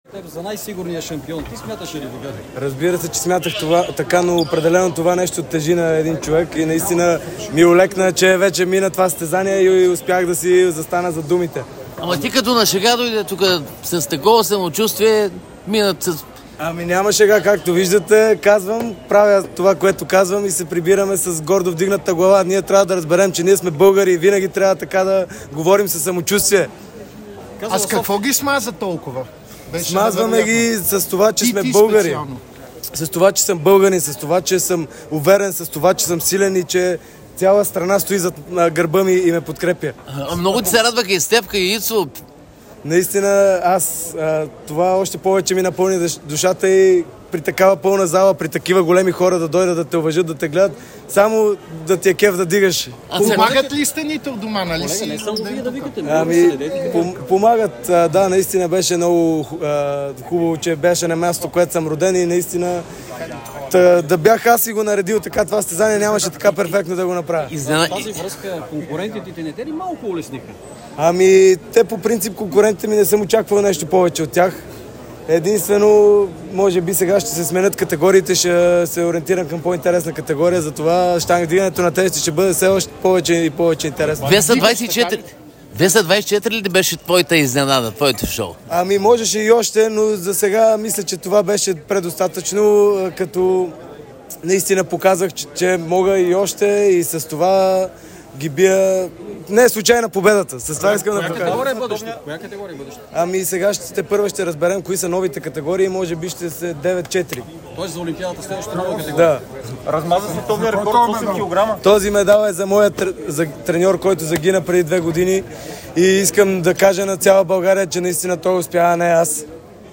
Олимпийският шампион по вдигане на тежески Карлос Насар говори непосредствено след страхотния си успех в родния си Париж.